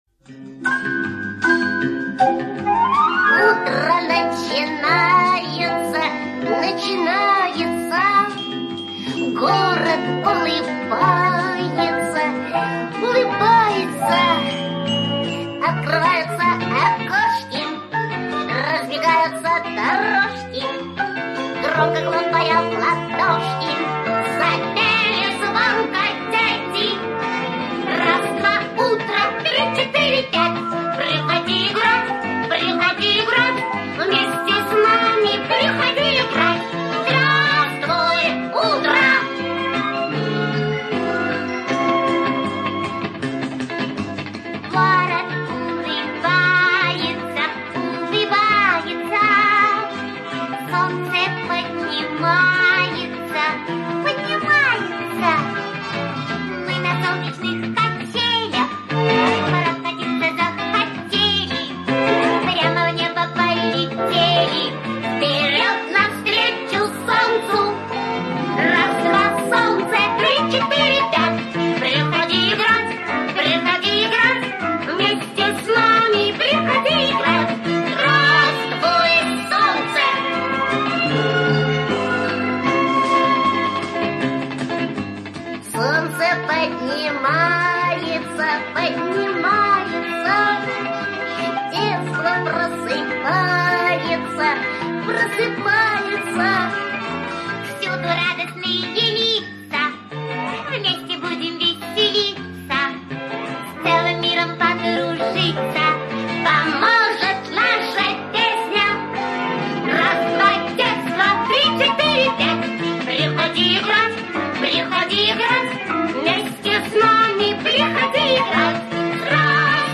Веселая популярная песенка для малышей